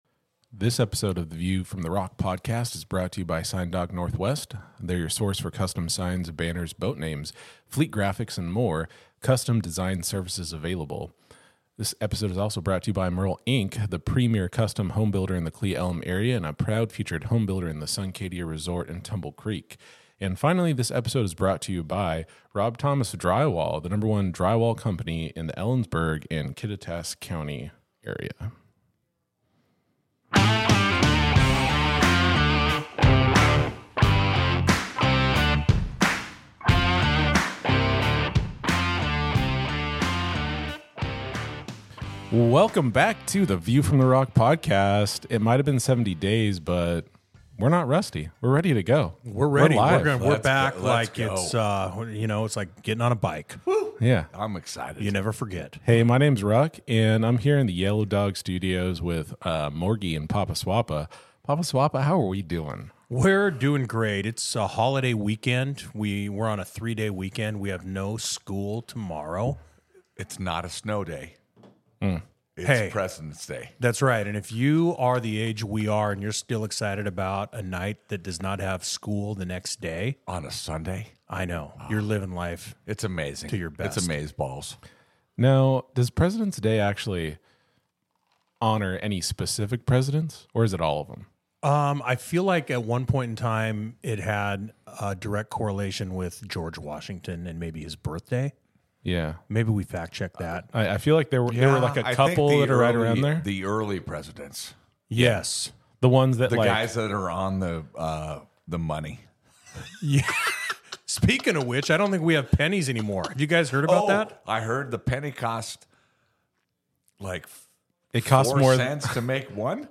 In this episode we catch up on what we've missed the last 70 days when we were on break. This episode is just us talk with a beer.